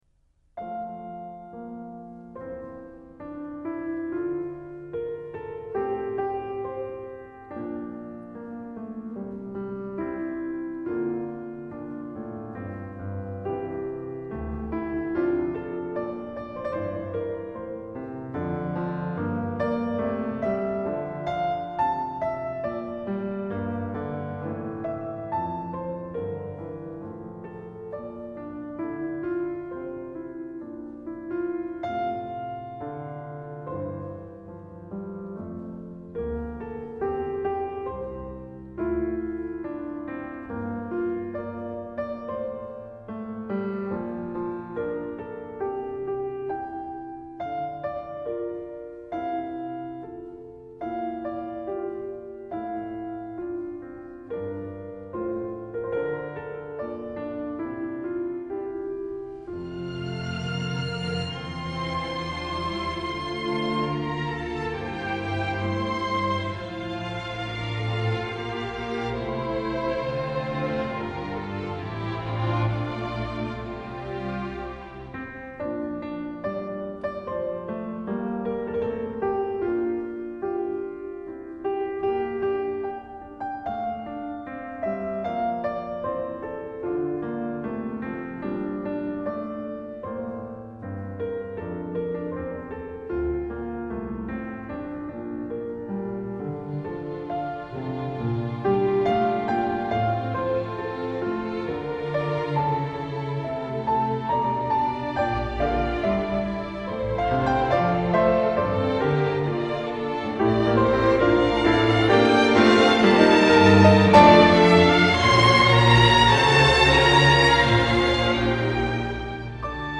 Eclogue ~1800 Czech Group: Instrumental Synonyms: Эклога An eclogue is a poem in a classical style on a pastoral (idill) subject. The term also been applied to pastoral music.